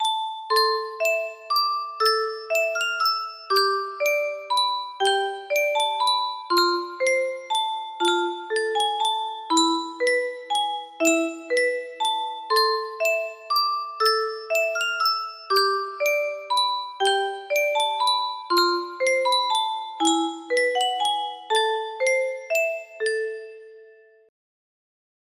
Clone of Green Sleeves music box melody
Grand Illusions 30 (F scale)